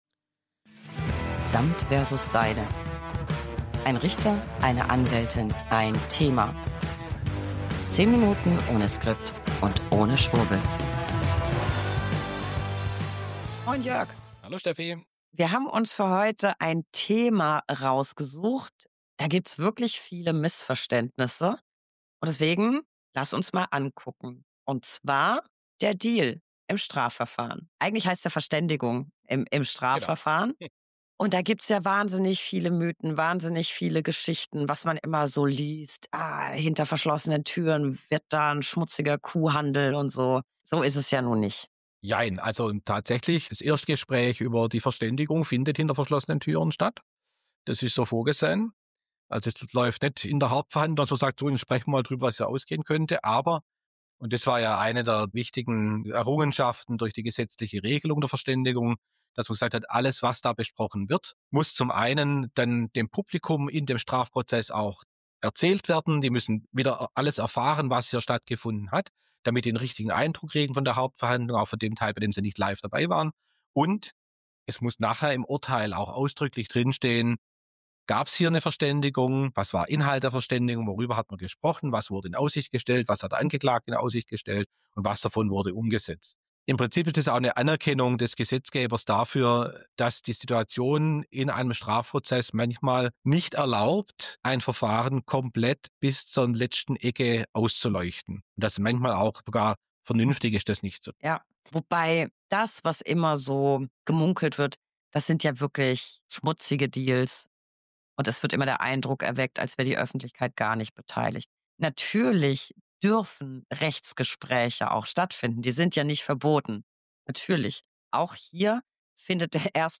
1 Anwältin + 1 Richter + 1 Thema.
10 Minuten ohne Skript und ohne Schwurbel.